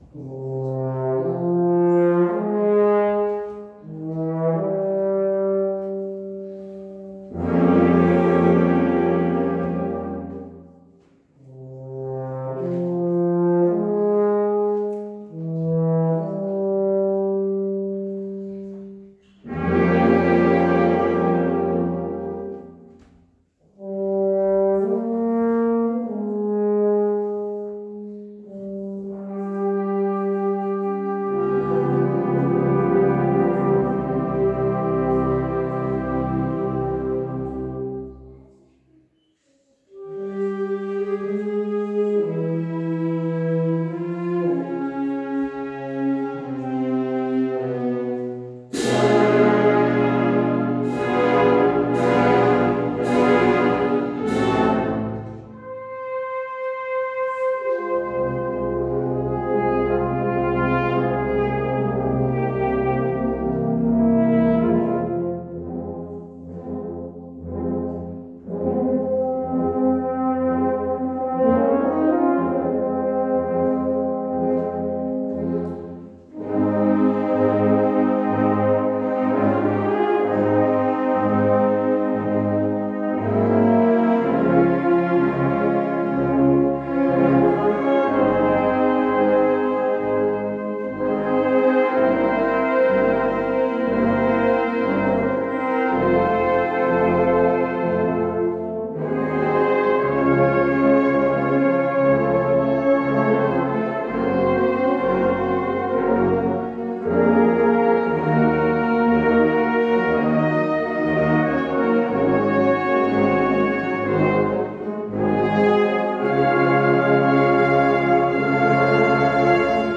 Mit dem Requiem von Julius Fucik erfolgte am Allerseelen-Tag 2019 ein äußerst würdiger Jahresabschluss.
Benedictus – Moto misterioso